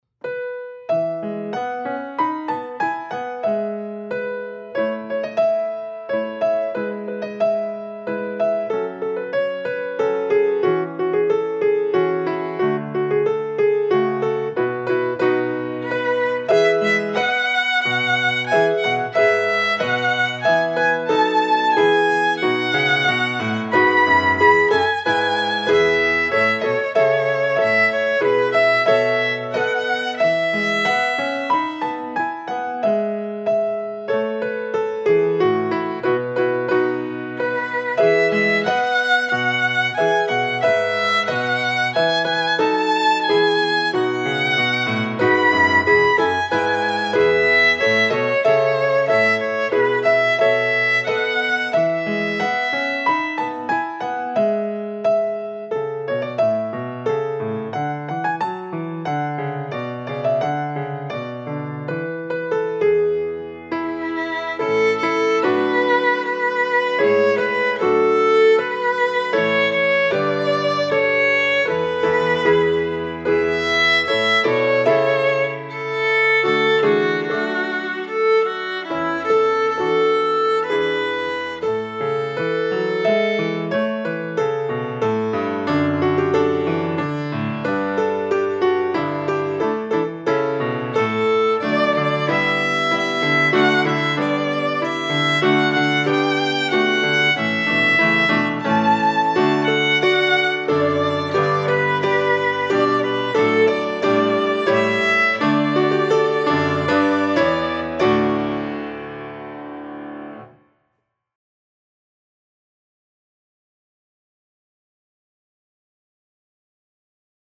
Easy Violin Solos for Thanksgiving